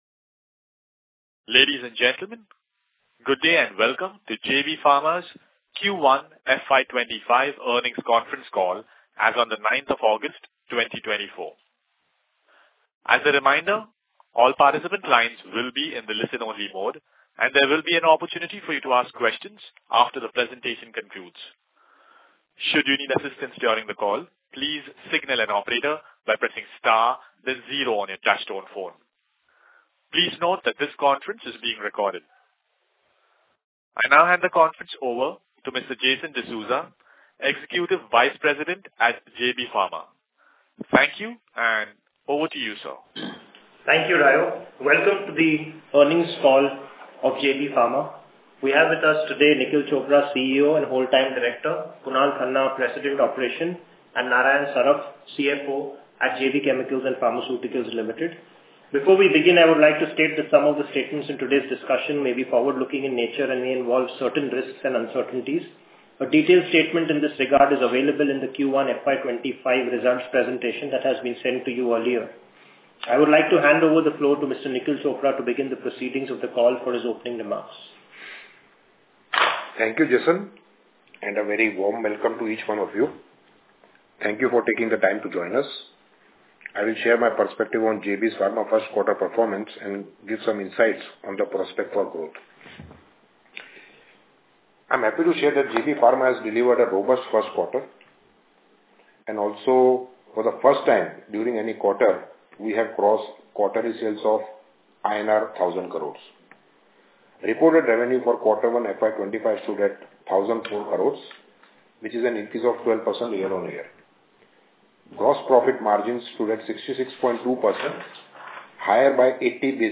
JB Pharma Q1 FY25 Earnings Call Audio